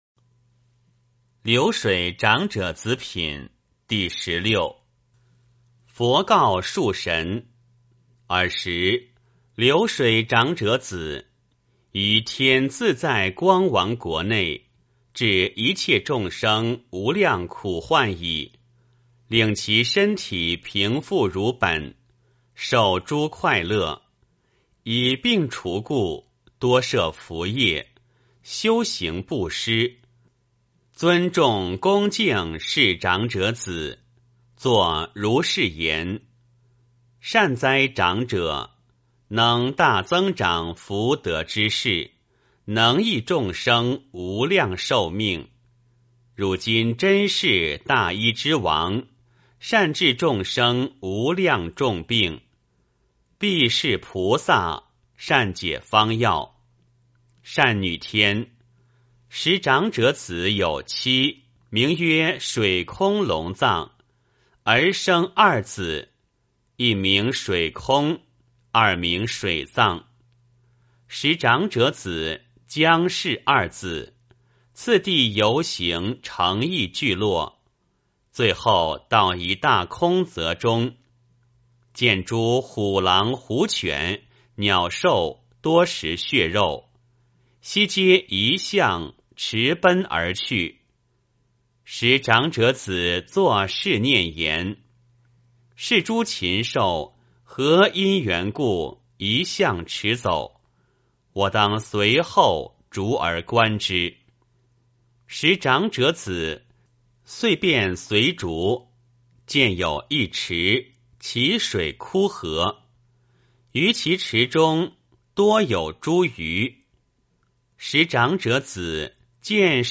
金光明经-流水长者子品第十六 诵经 金光明经-流水长者子品第十六--未知 点我： 标签: 佛音 诵经 佛教音乐 返回列表 上一篇： 金光明经-善集品第十二 下一篇： 金光明经-赞佛品第十八 相关文章 佛光三昧修持法--佛光山梵呗团 佛光三昧修持法--佛光山梵呗团...